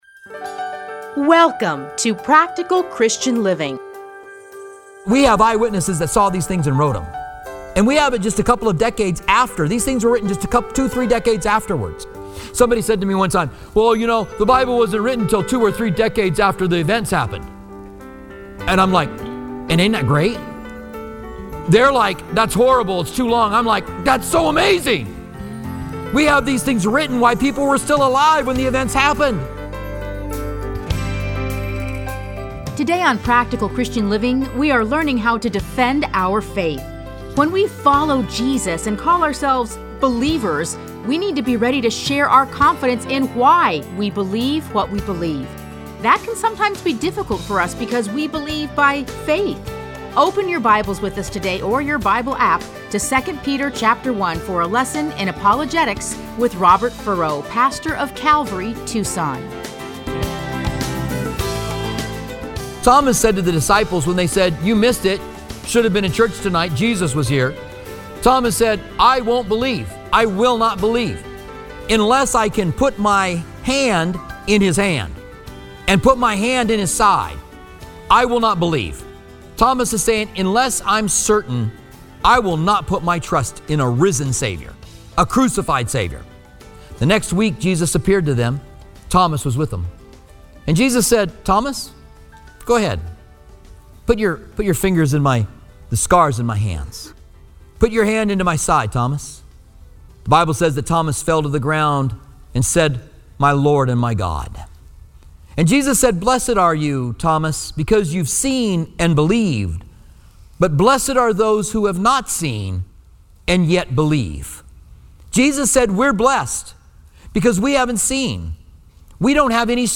Listen to a teaching from 2 Peter 1:16-21.